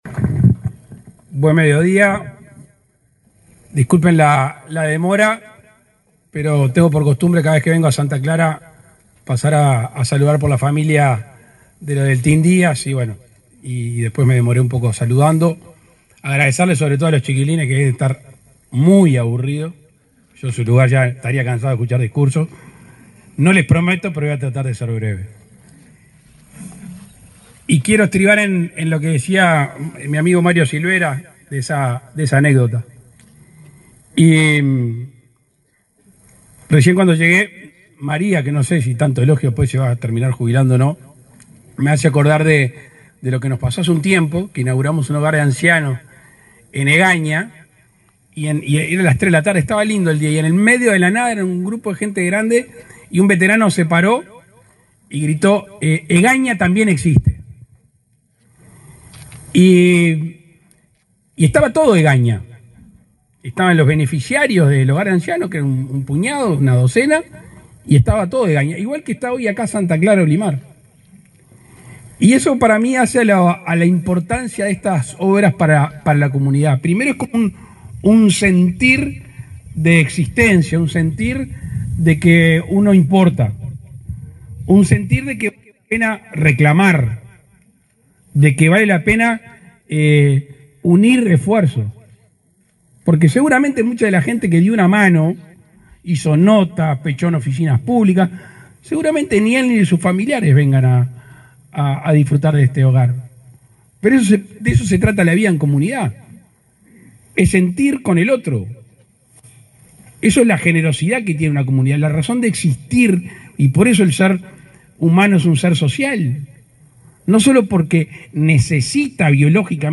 Palabras del presidente Luis Lacalle Pou
Palabras del presidente Luis Lacalle Pou 03/10/2024 Compartir Facebook X Copiar enlace WhatsApp LinkedIn Este jueves 3, el presidente Luis Lacalle Pou participó de la inauguración de un hogar de ancianos en Santa Clara de Olimar, departamento de Treinta y Tres.